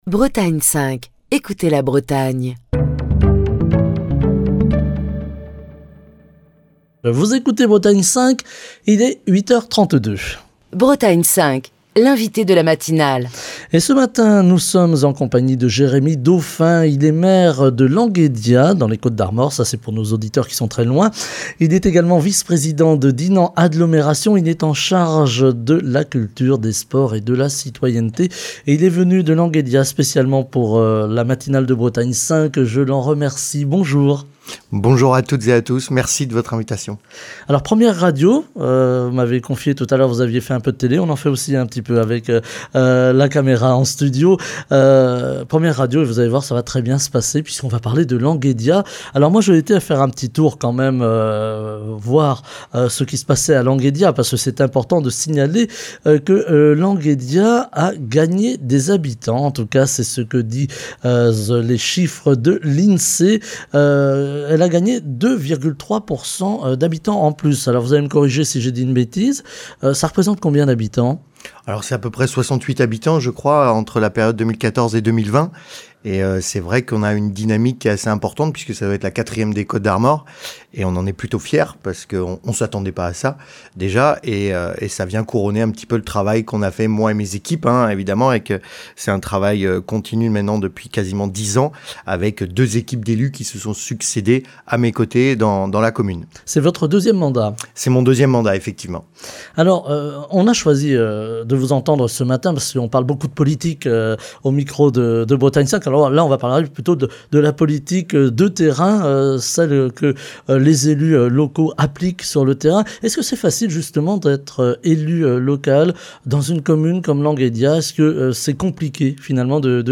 Jérémy Dauphin, maire de Languédias, vice-Président de Dinan Agglomération en charge de la Culture, des Sports et de la Citoyenneté est l'invité de la matinale de Bretagne 5.